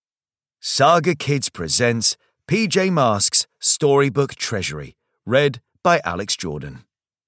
PJ Masks - Storybook Treasury (EN) audiokniha
Ukázka z knihy